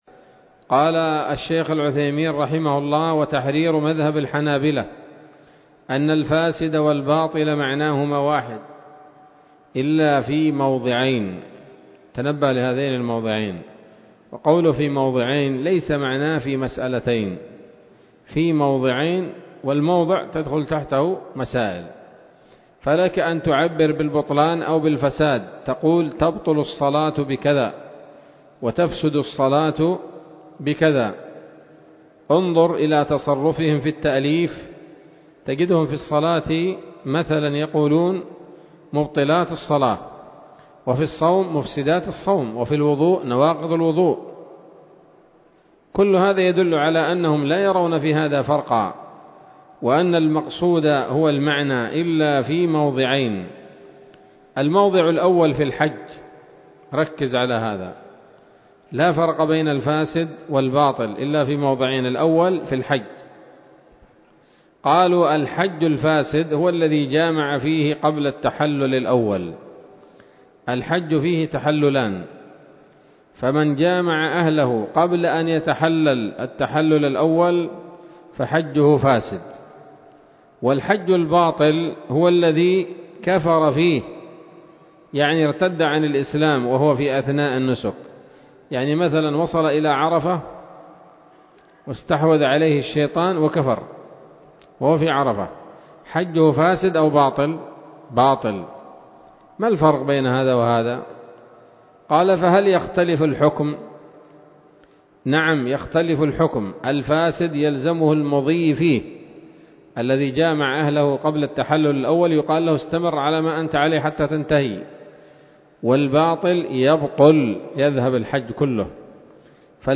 الدرس الثامن عشر من شرح نظم الورقات للعلامة العثيمين رحمه الله تعالى